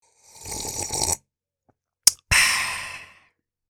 slurp.mp3